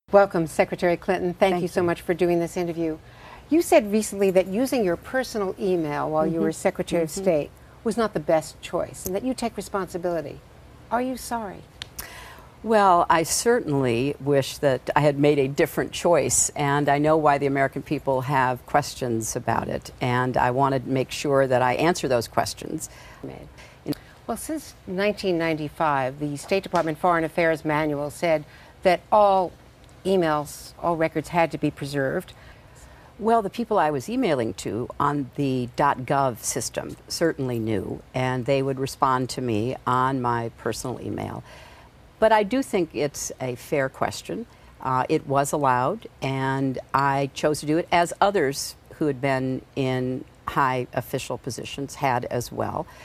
small_two_speaker.mp3